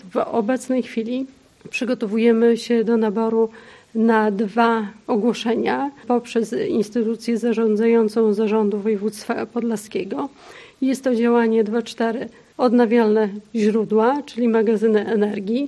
O charakterze inwestycji mówiła podczas konferencji prasowej Prezes zarządu, wicestarosta łomżyński, Maria Dziekońska: